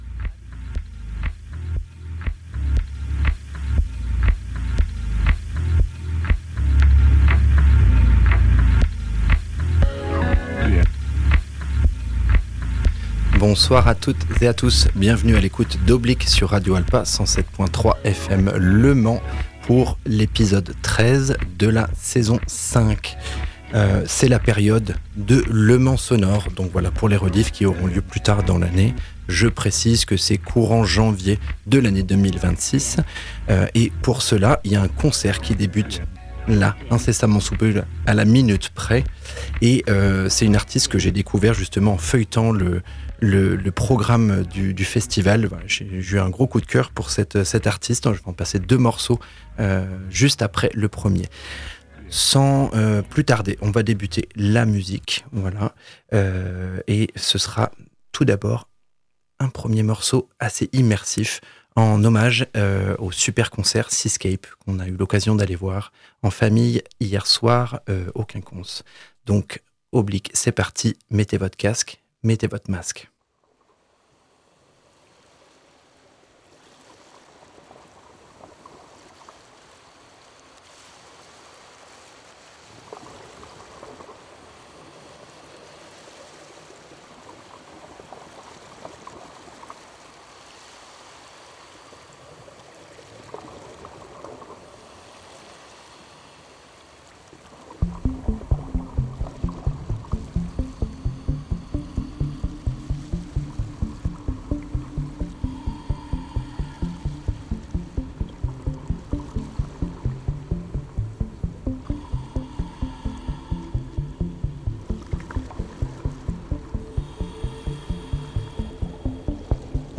CHILL DOWNTEMPO